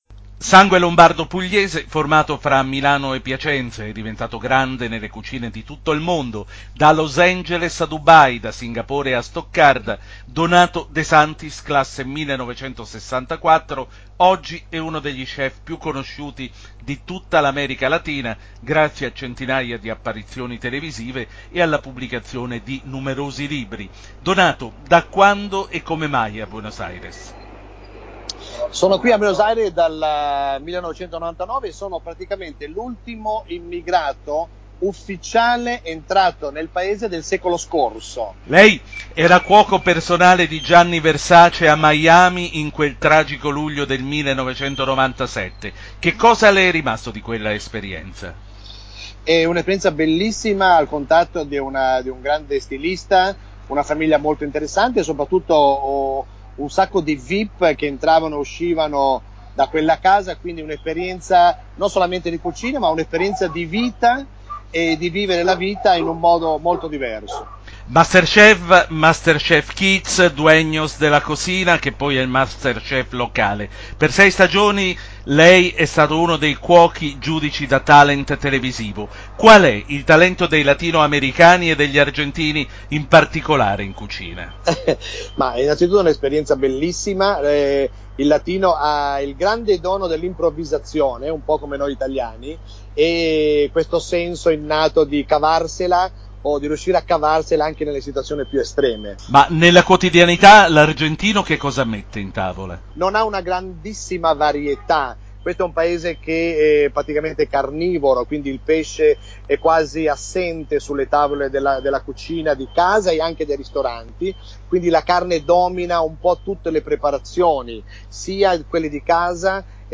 con l’intervista al cuoco più famoso in Argentina, l’italiano Donato De Santis